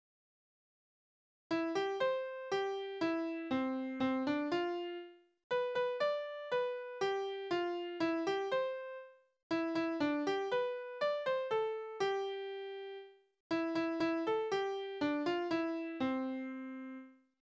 Tonart: C-Dur
Taktart: 4/4